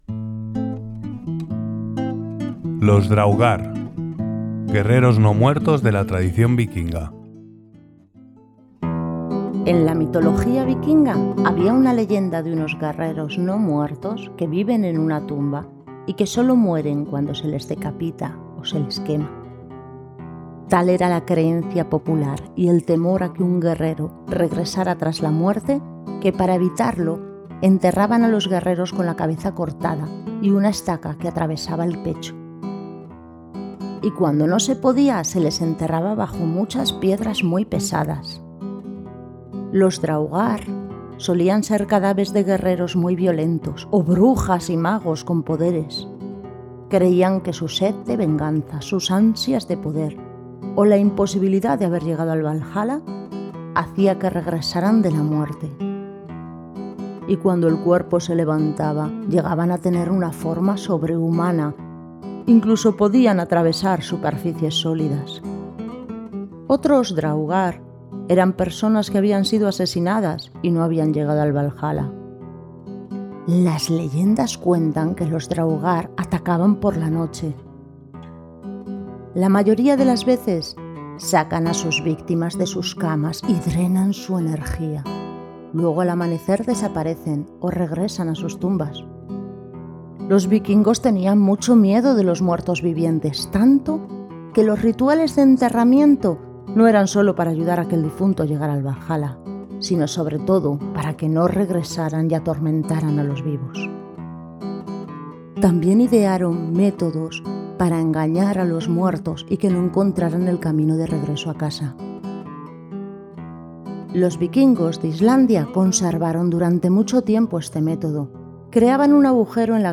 🔊 Narrado en Español-Castellano (voz humana)
💀-los-draugr-⚔-guerreros-no-muertos-vikingos-🎸-narrado-en-espanol.mp3